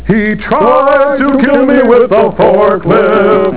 Below is my collection of sounds from the TV show MST3K and MST3K the movie.